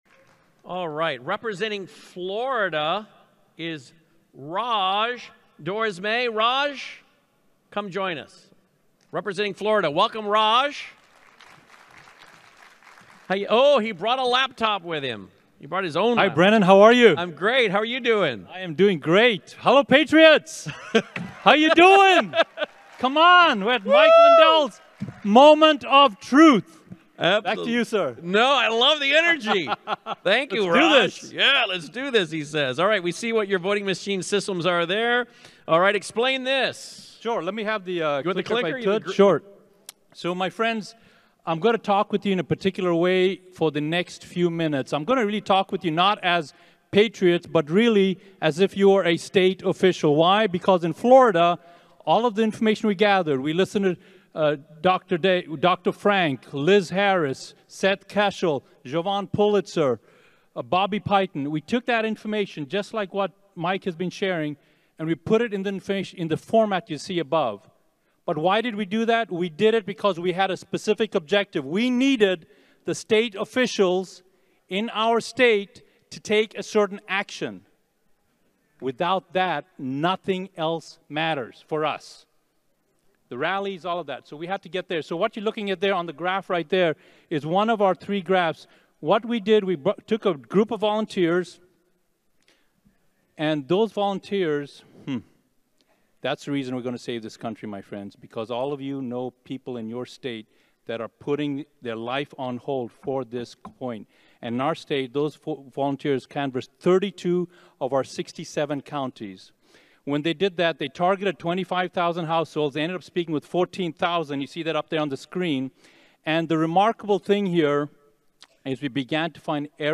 presentation